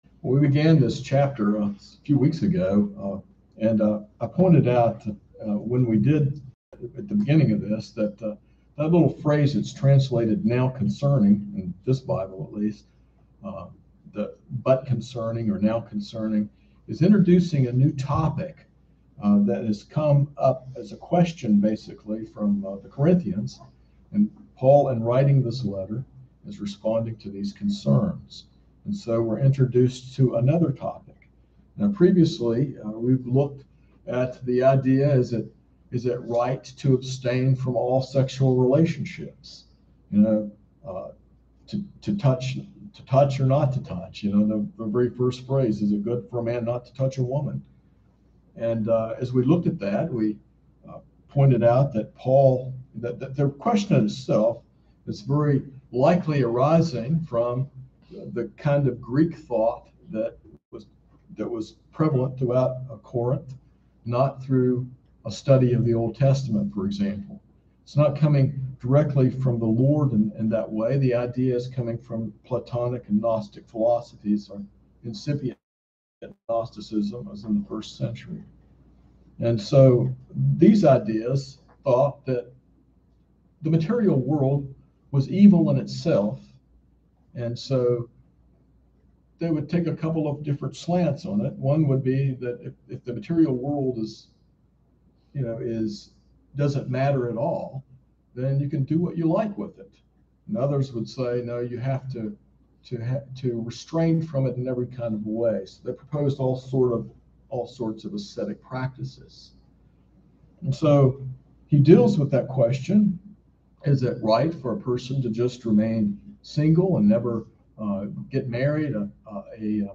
This sermon explores how believers can live faithfully for God’s glory in all circumstances, focusing on marital status, worldly concerns, and undistracted devotion amidst life’s challenges and impending distress.
sermon-8-8-21.mp3